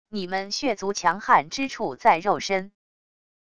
你们血族强悍之处在肉身wav音频生成系统WAV Audio Player